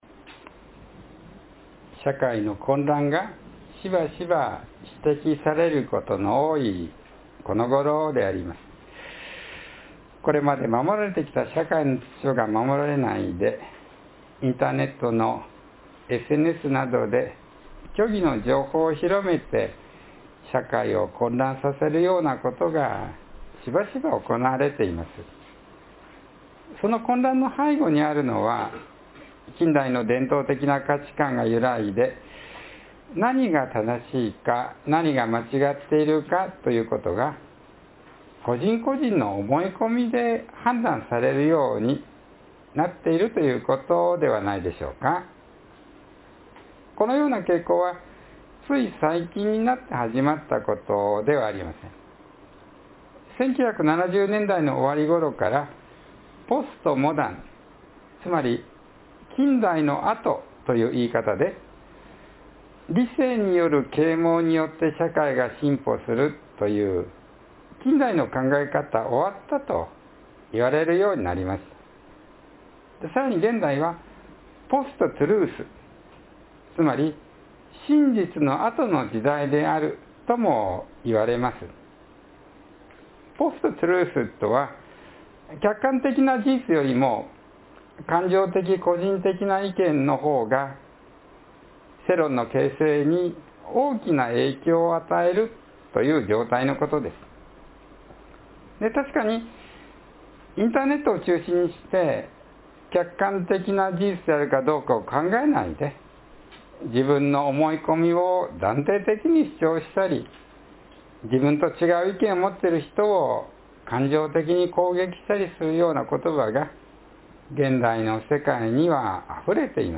（12月7日の説教より）